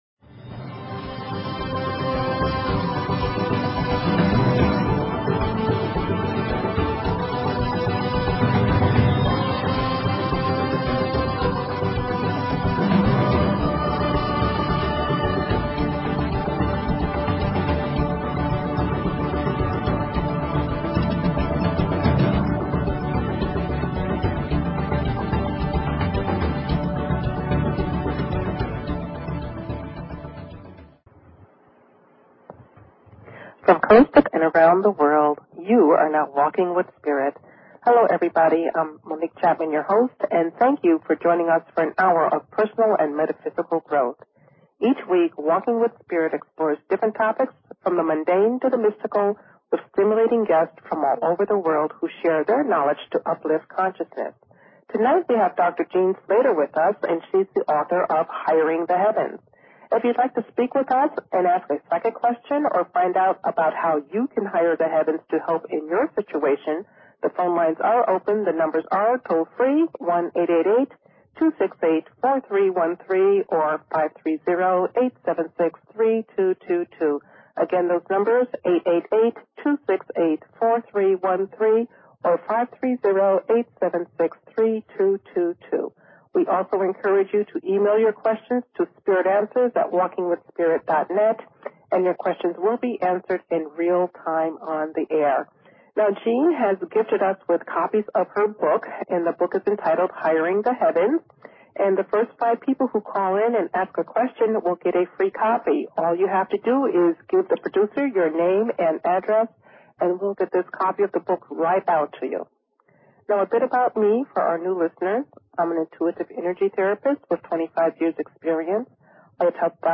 Talk Show Episode, Audio Podcast, Walking_with_Spirit and Courtesy of BBS Radio on , show guests , about , categorized as